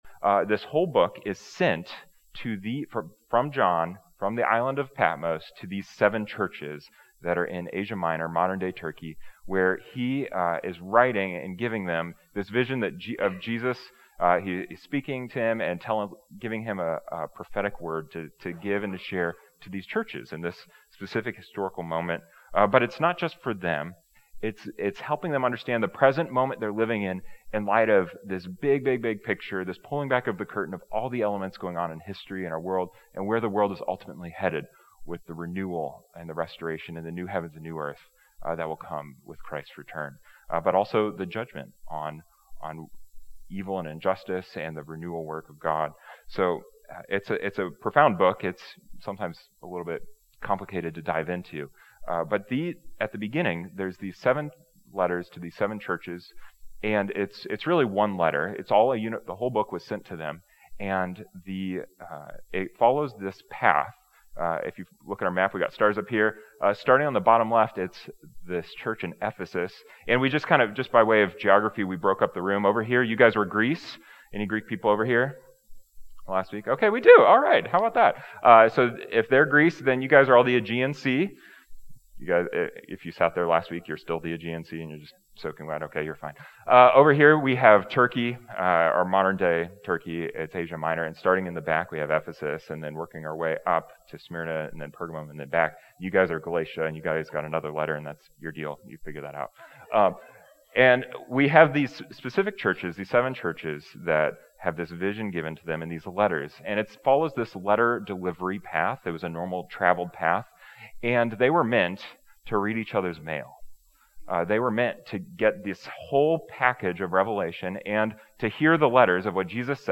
Today looks at Pergamum, the Compromising Church. We'll be taking communion at the end of the message, so feel free to grab the elements for that to participate from wherever you are.